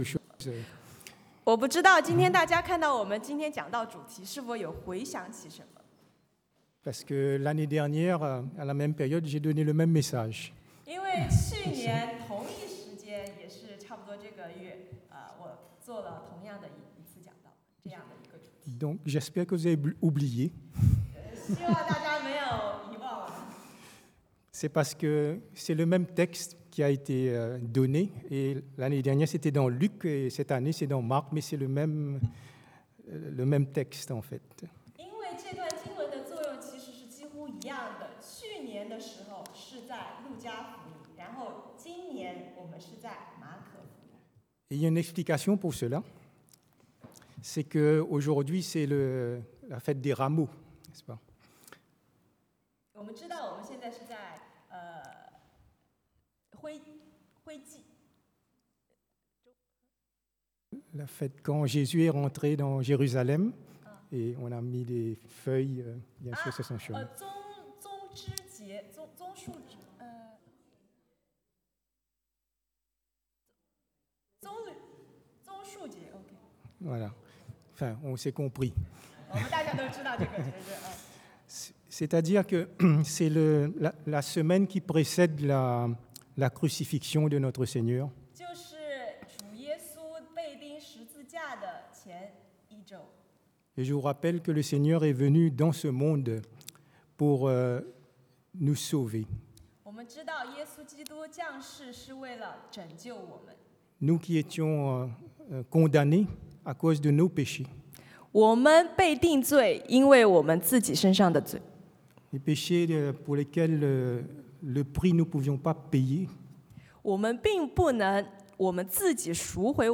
Passage: Marc 馬可福音 11:1-11 Type De Service: Predication du dimanche « La base de la fondation 立根之本 Jésus est vraiment ressuscité